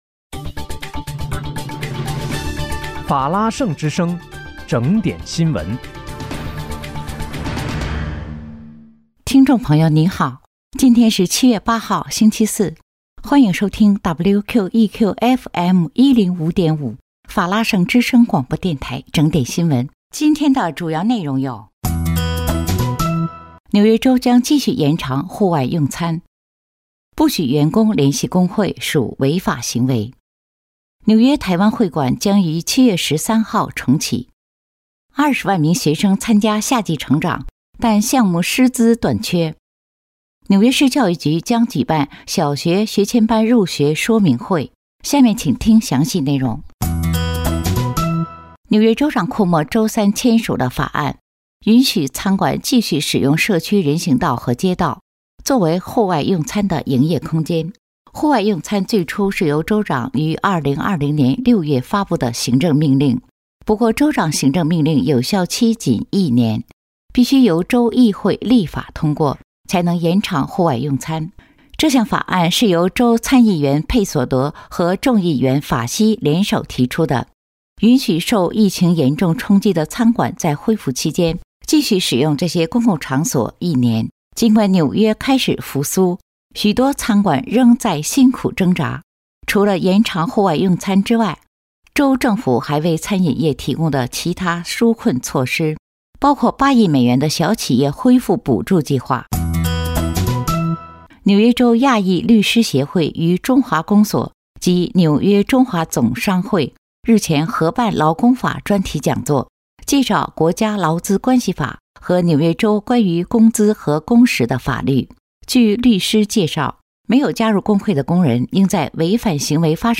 7月8日（星期四）纽约整点新闻